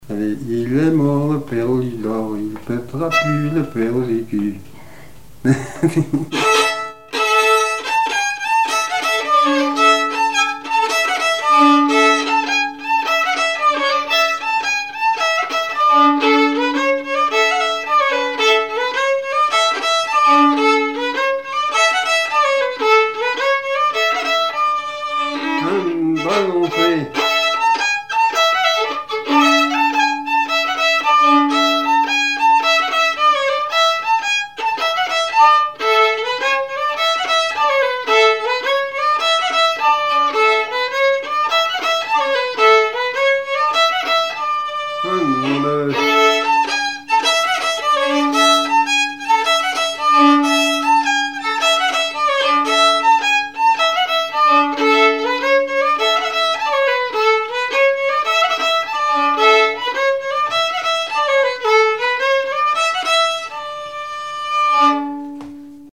Couplets à danser
branle : avant-deux
Témoignages et chansons
Pièce musicale inédite